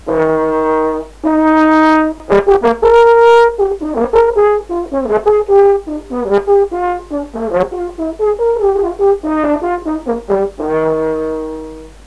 Horn Playing
horn.wav